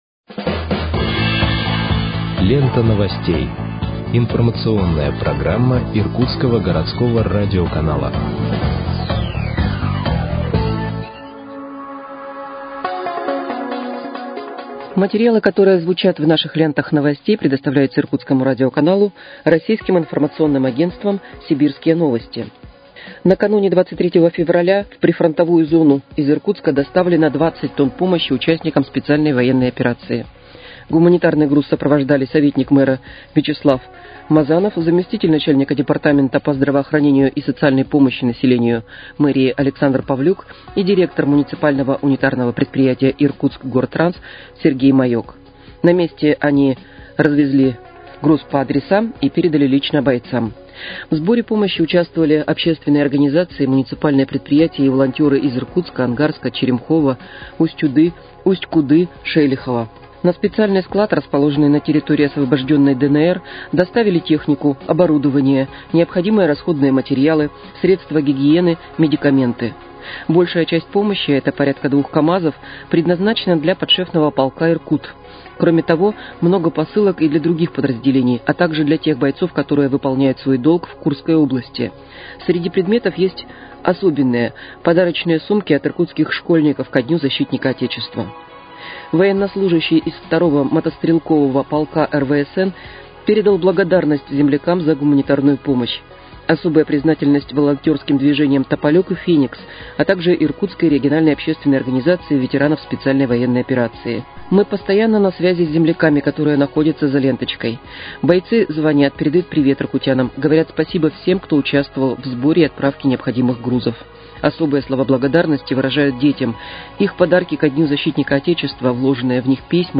Выпуск новостей в подкастах газеты «Иркутск» от 28.02.2025 № 1